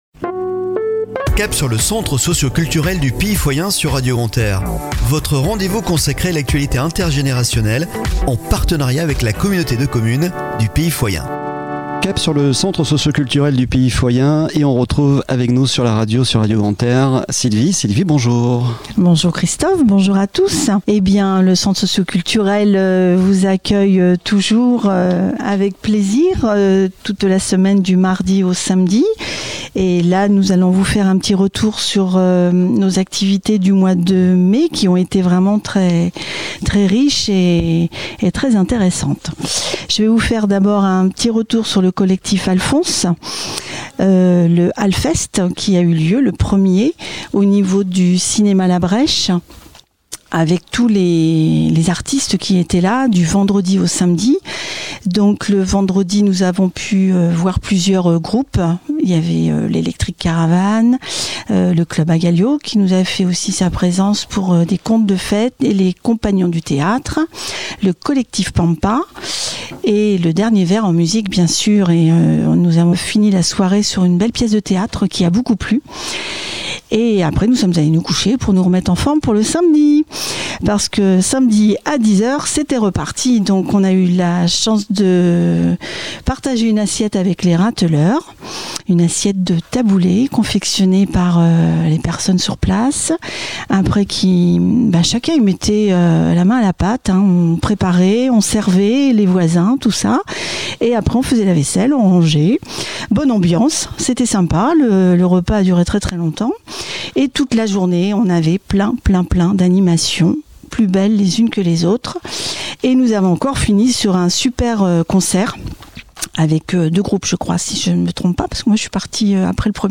A écouter chaque Lundi à 09h30 et 17h30 , et Mercredi à 12h30 et 19h30 sur Radio Grand "R" en partenariat avec la Communauté de Communes du Pays Foyen